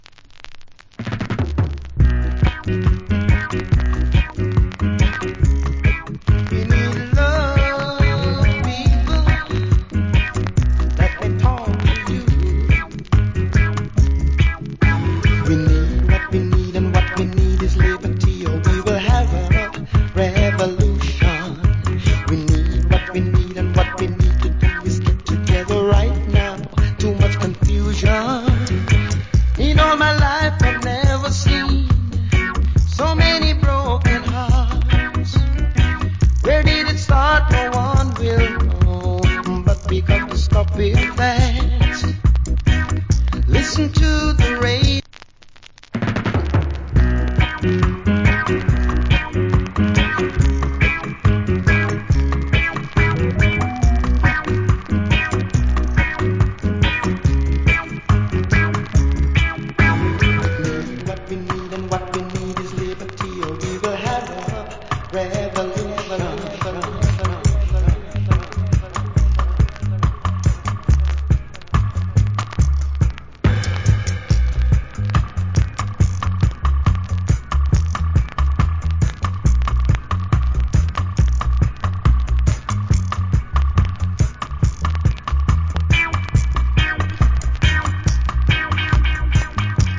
Old Hits Reggae.